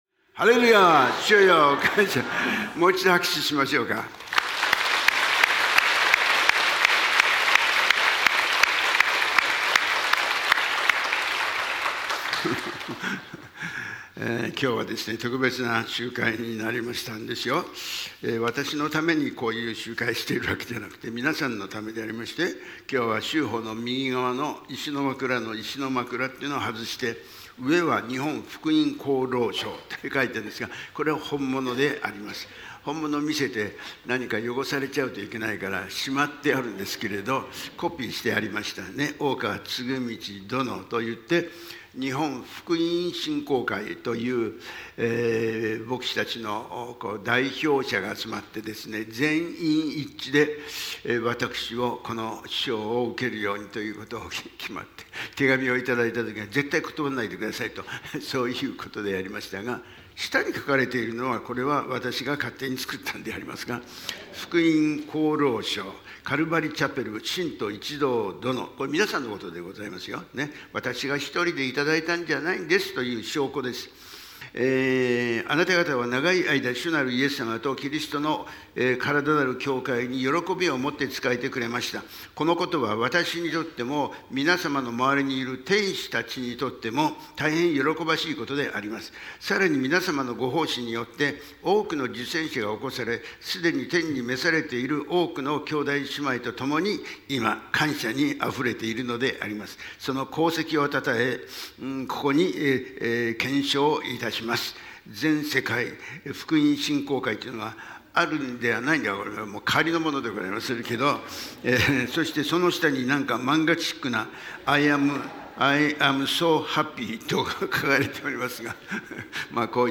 メッセージ